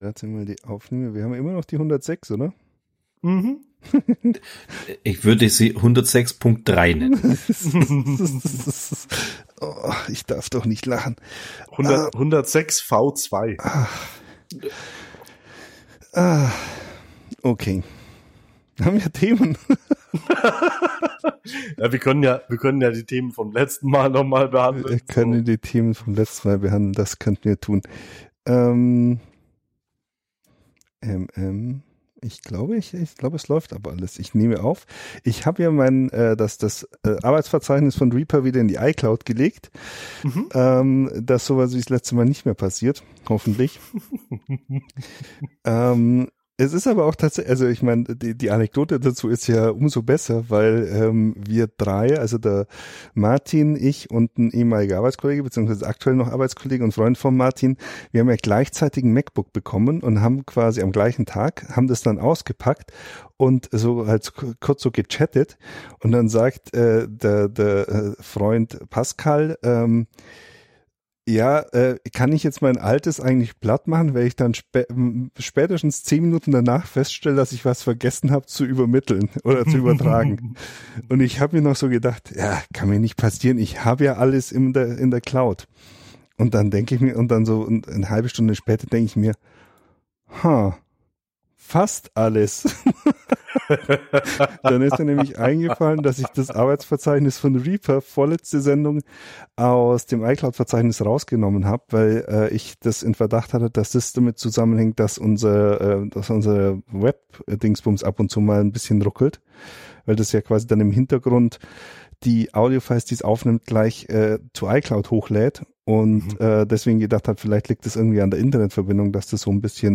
Im Podcast ein Gespräch über neue Sicherheitstechnik in Autos und schlechte Erfahrungen.